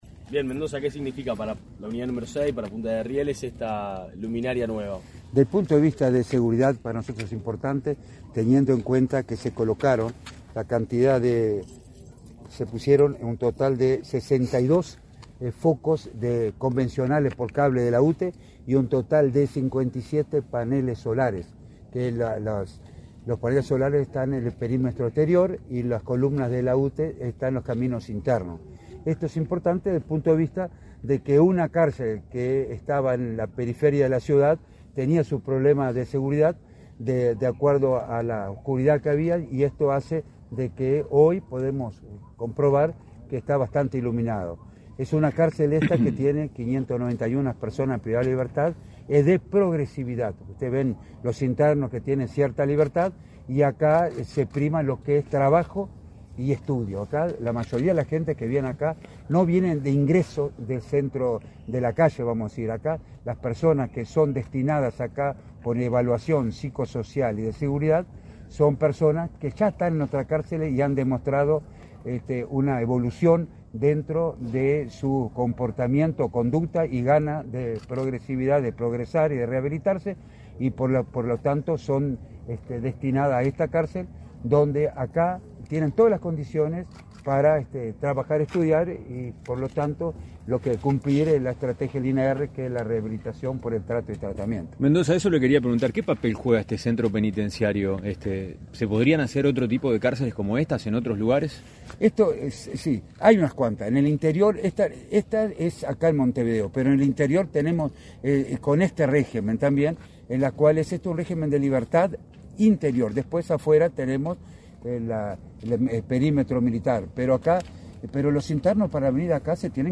Declaraciones a la pensa del director del INR, Luis Mendoza
Declaraciones a la pensa del director del INR, Luis Mendoza 29/05/2024 Compartir Facebook X Copiar enlace WhatsApp LinkedIn El Ministerio del Interior, a través del Instituto Nacional de Rehabilitación (INR), inauguró, este 29 de mayo, la red lumínica de la Unidad n.°6 de Punta de Rieles, en Montevideo. Tras el evento, el director del INR, Luis Mendoza, realizó declaraciones a la prensa.